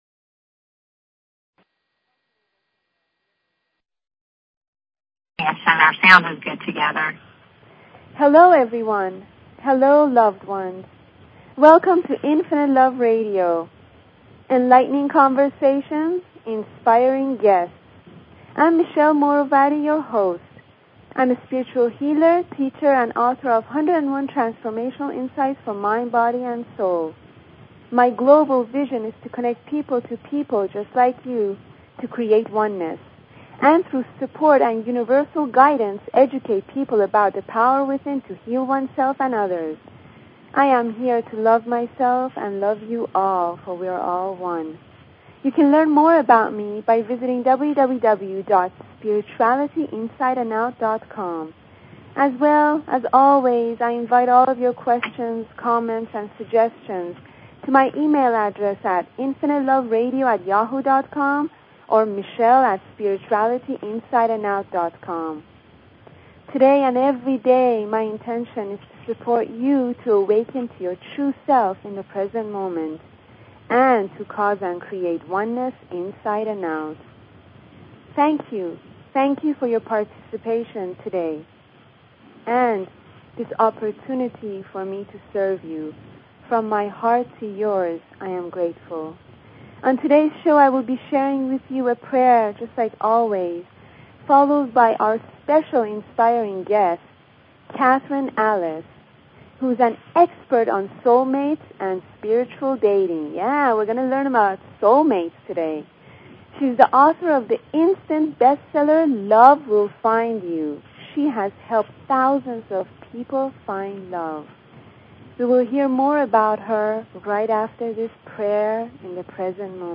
Talk Show Episode, Audio Podcast, Infinite_Love_Radio and Courtesy of BBS Radio on , show guests , about , categorized as